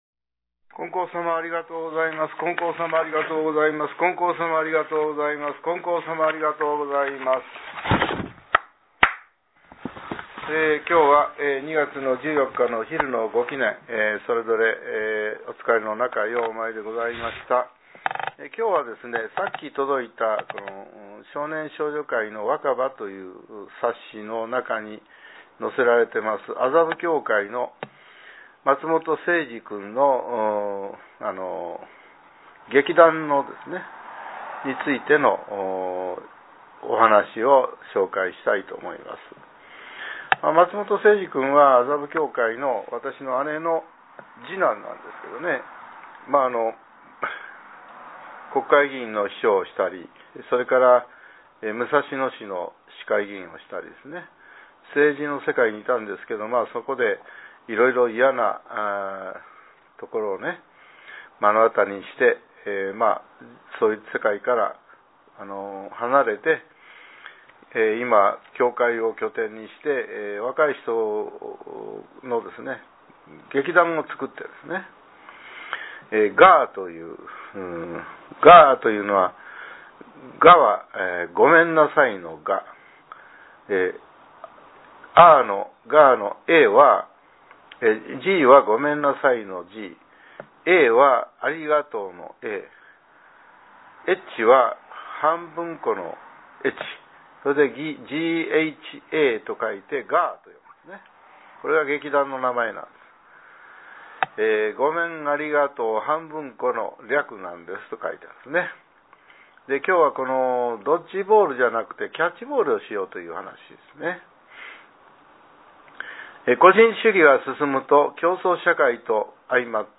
令和８年２月１５日（昼）のお話が、音声ブログとして更新させれています。